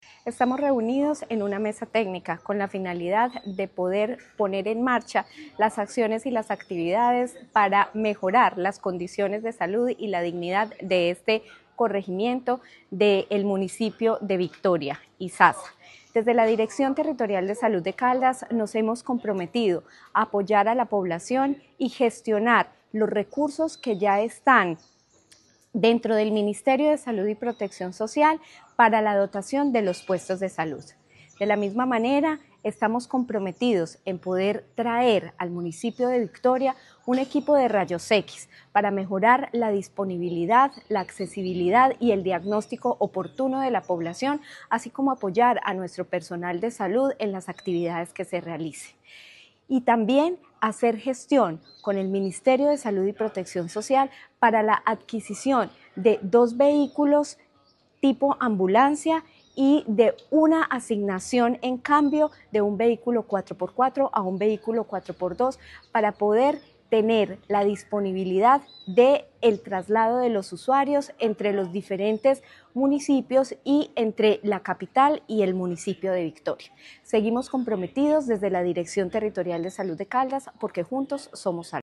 Natalia Castaño Díaz, directora Territorial de Salud de Caldas.